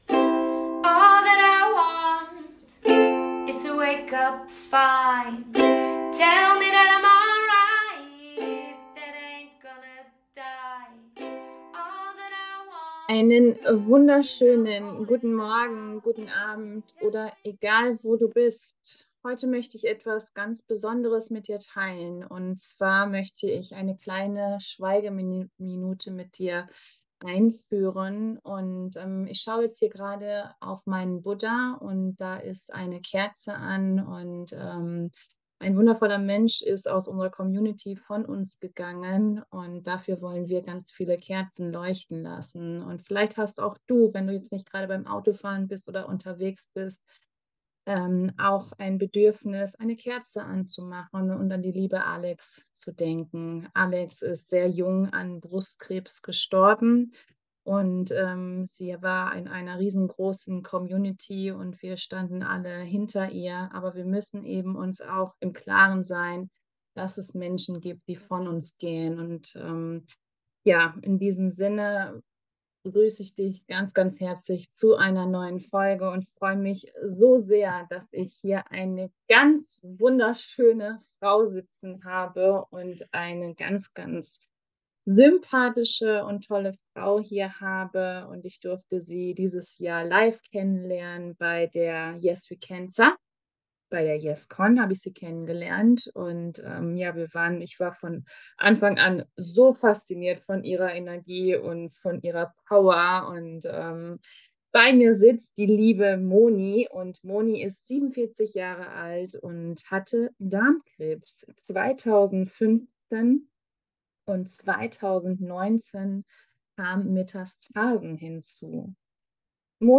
Mutmacher Gespräch